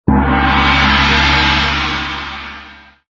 messagealert5.mp3